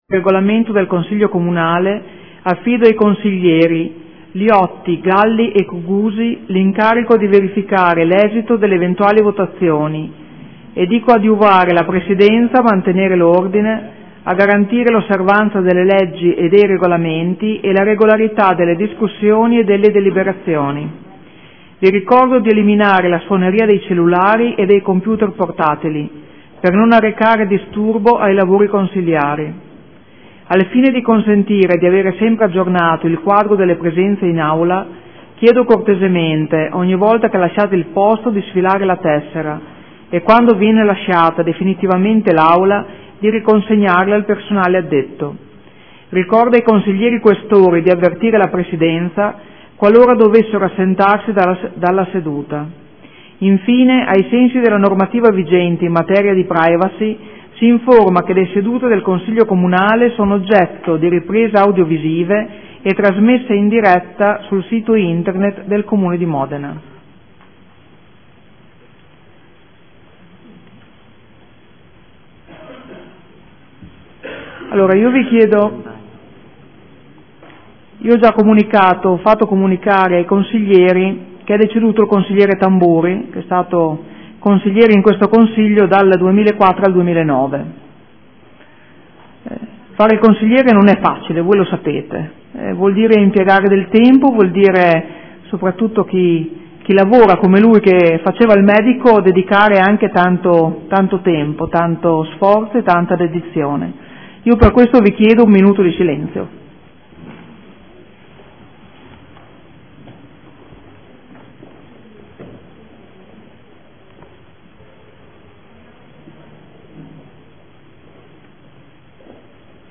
Seduta del 05/02/2015 Il Presidente Francesca Maletti apre i lavori del Consiglio. Chiede all'Assemblea un minuto di silenzio in memoria dell’ex consigliere comunale Mario Tamburi, deceduto lo scorso 2 febbraio.